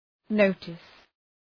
Προφορά
{‘nəʋtıs}